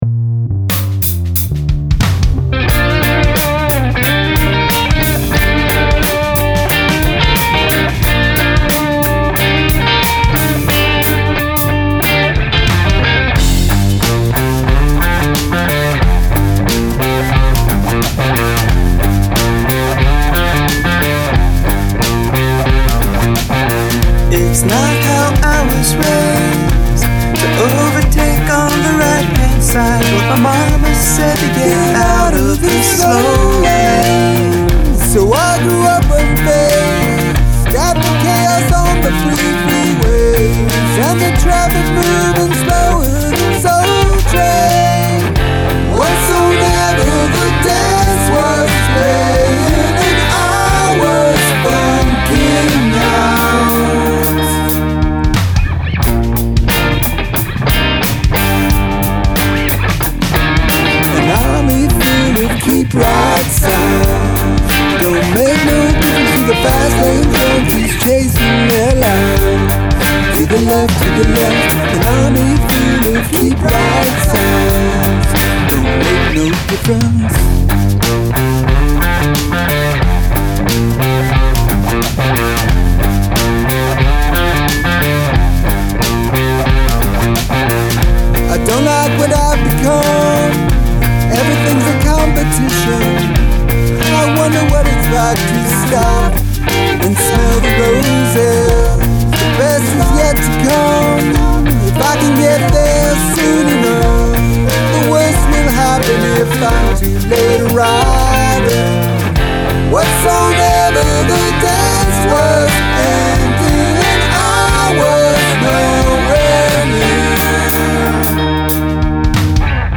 I like the guitar riff.
Oh man, the drums in that double-time section rock!